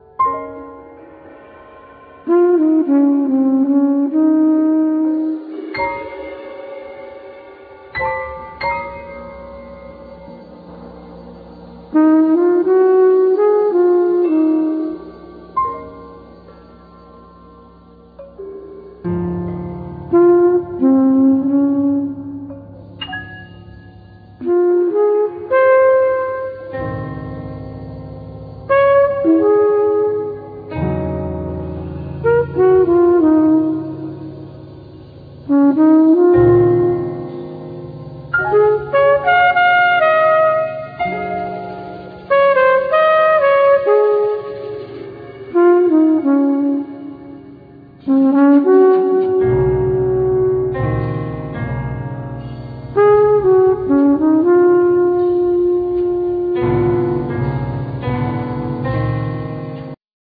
Trumpet
Piano
Percussion